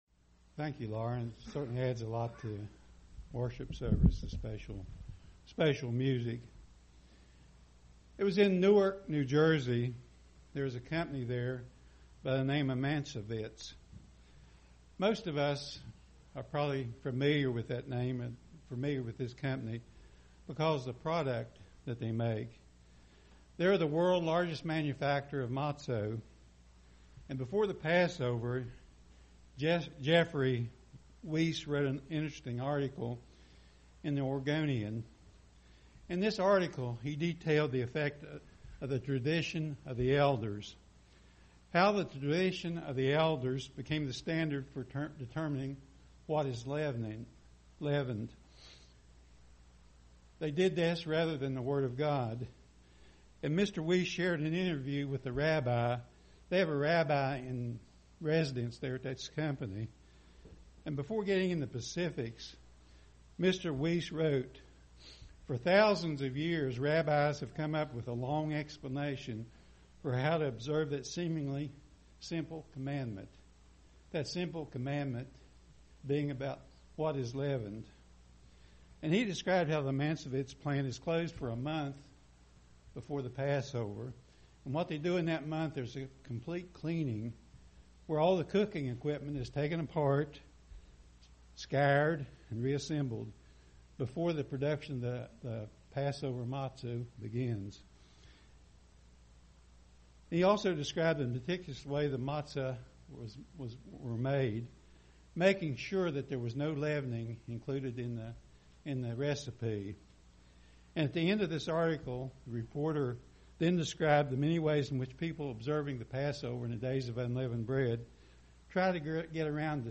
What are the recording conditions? Given in Portsmouth, OH